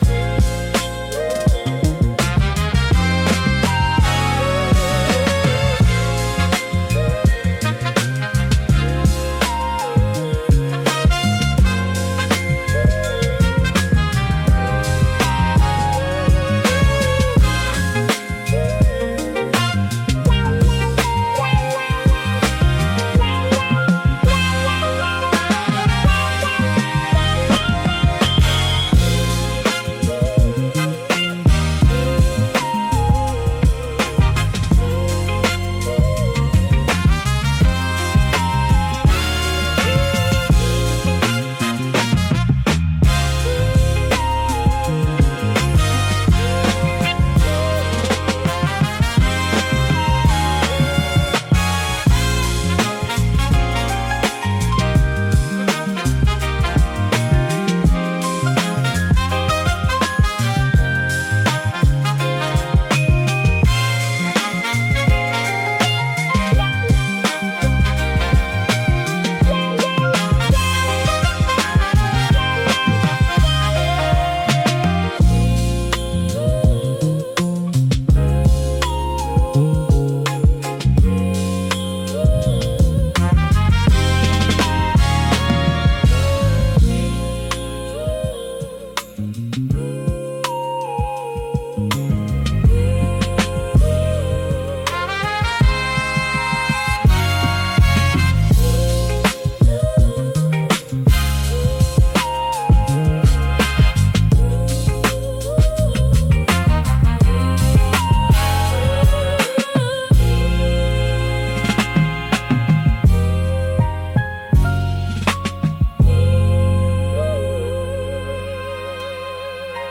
Soul, Vintage, Hip Hop, Elegant, Positive